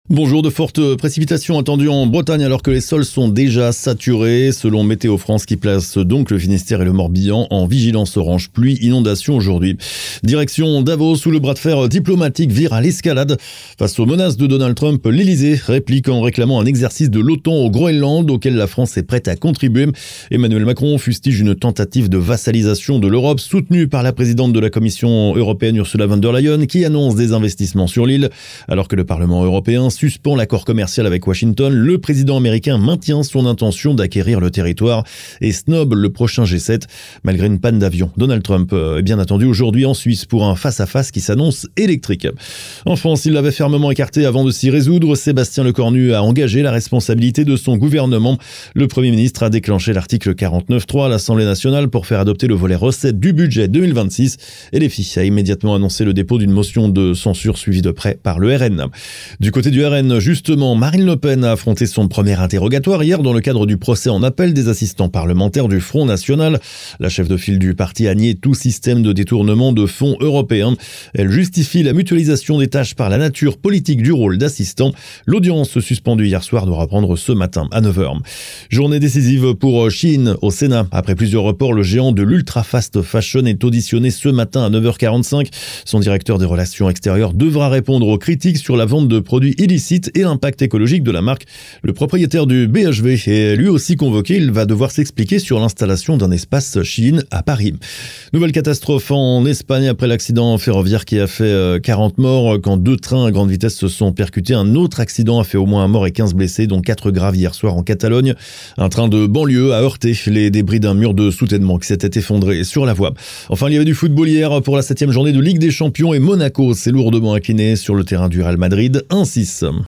Flash infos 21/01/2026